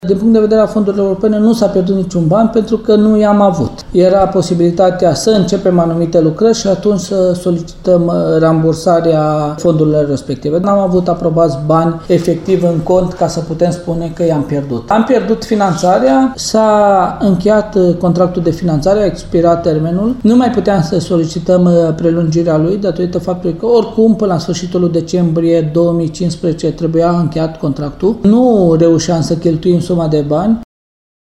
Vicepresedintele CJT, Calin Dobra, argumentează renunţarea la proiectul semnat in 2010, spunând că s-a facut din motive obiective